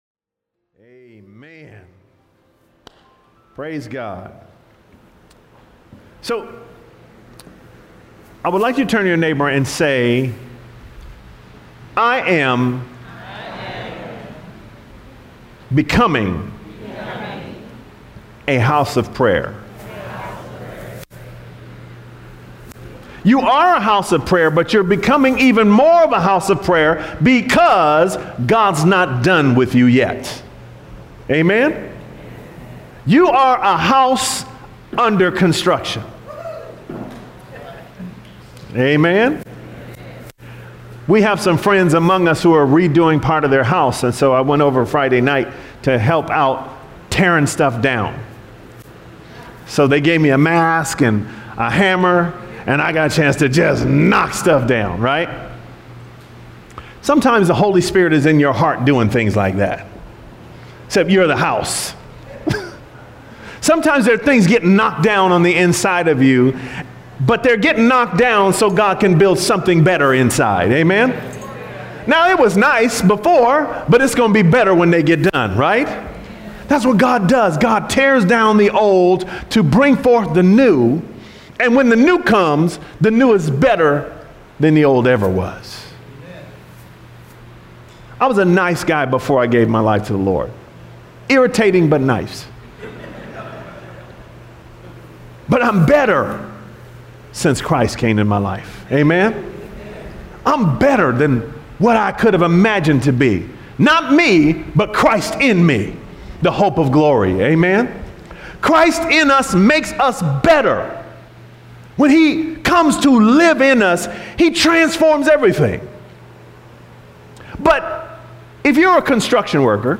Sermons Archive - Page 62 of 84 - Abundant Life Worship Center